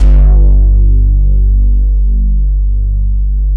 110 BASS  -R.wav